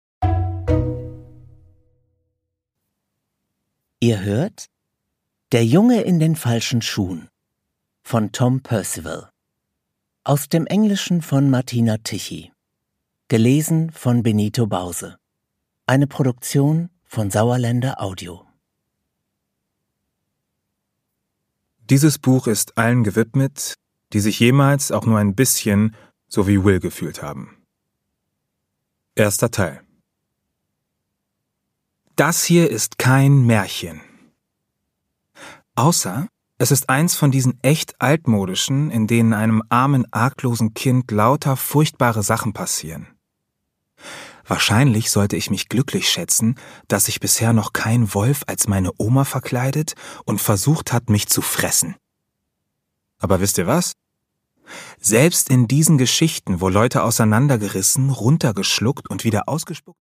Produkttyp: Hörbuch-Download
Gelesen von: Benito Bause
Benito Bause, u. a. bekannt durch den erfolgreiche TV-Serie Doppelhaushälfte, erzählt warm und authentisch.